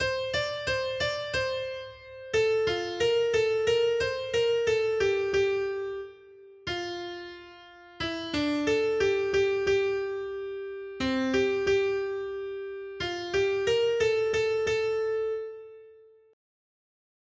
Noty Štítky, zpěvníky ol36.pdf responsoriální žalm Žaltář (Olejník) 36 Ž 89, 4-5 Ž 89, 16-17 Ž 89, 27 Ž 89, 29 Skrýt akordy R: Na věky chci zpívat o Hospodinových milostech. 1.